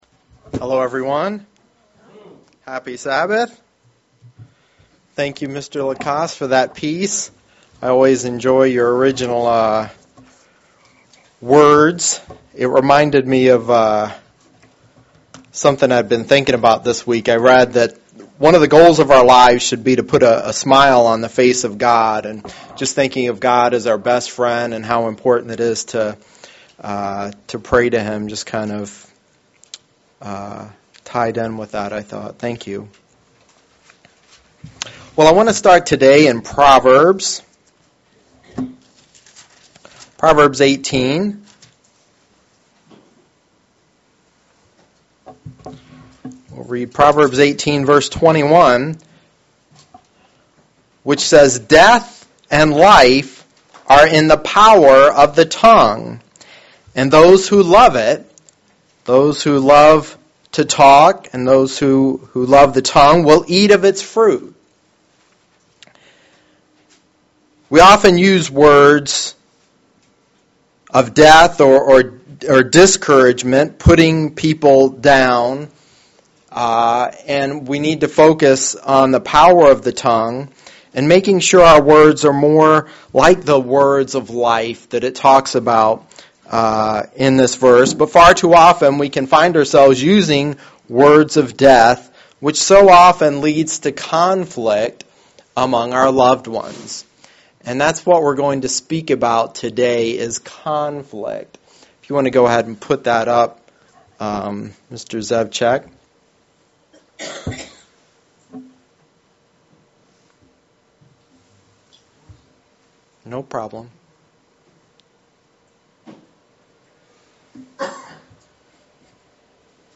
Given in Lansing, MI
UCG Sermon